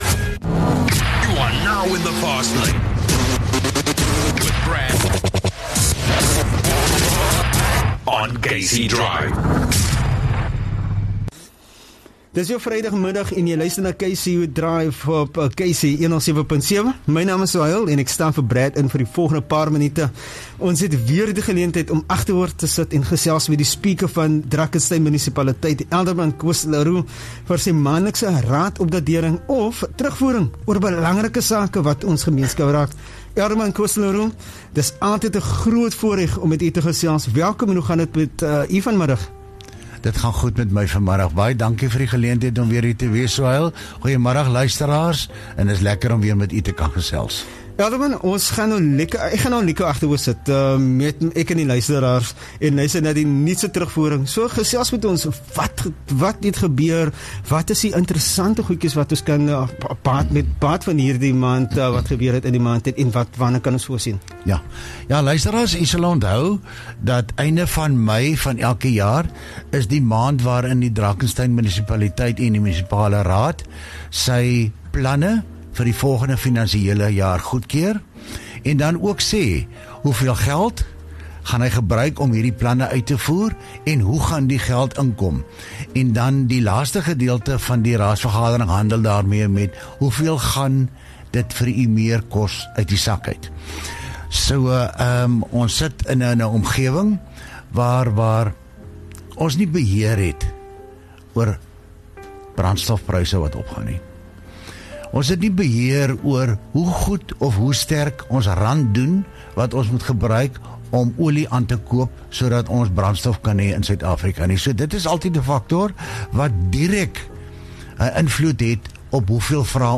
Drakensten speaker Alderman Koos Le Roux unpacks the latest Council updates